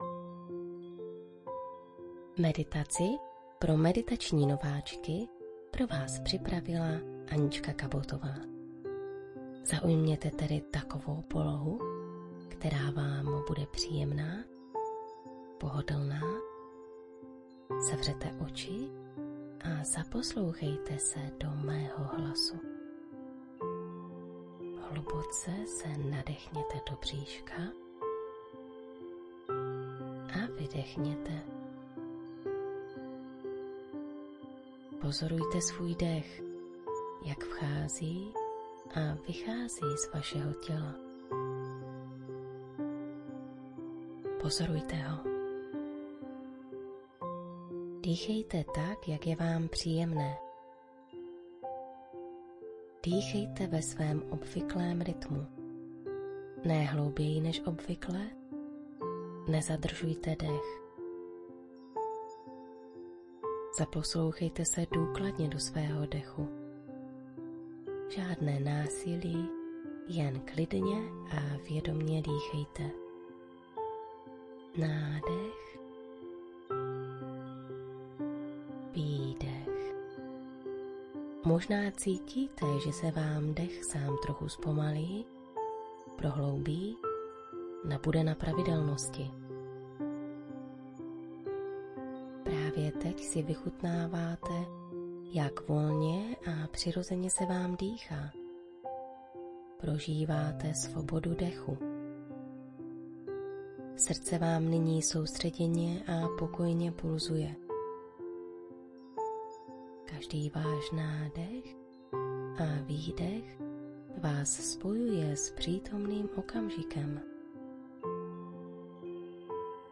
V dalších dvou máte na výběr ze dvou různých skladeb.
Meditace-pro-nováčky-3-min-hudba1.mp3